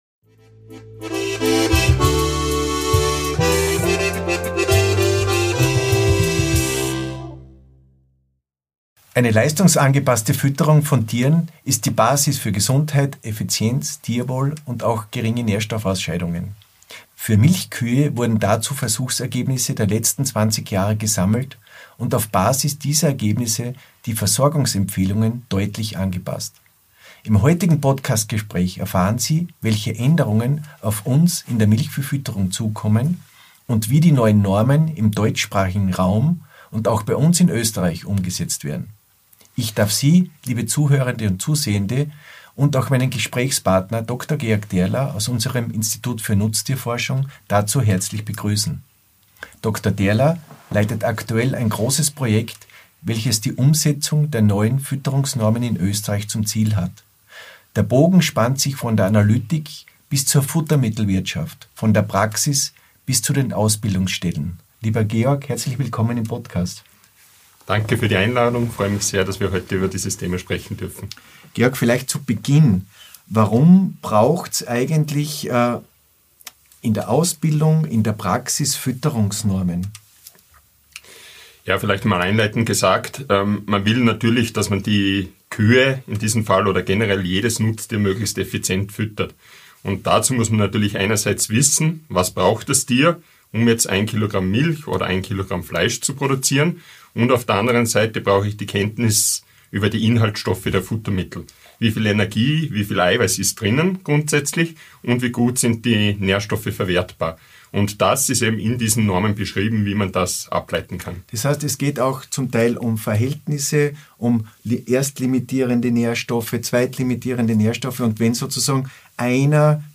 Im Podcast-Gespräch